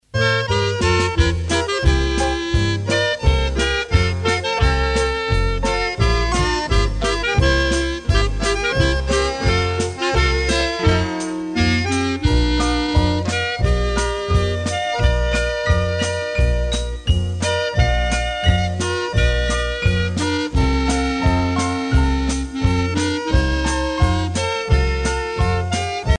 danse : fox-trot
Pièce musicale éditée